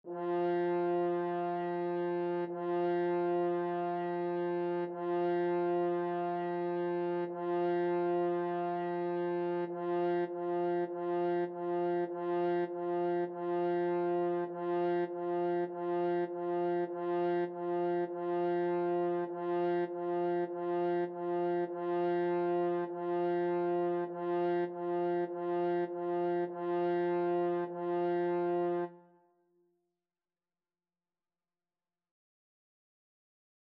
4/4 (View more 4/4 Music)
F4-F4
Instrument:
French Horn  (View more Beginners French Horn Music)
Classical (View more Classical French Horn Music)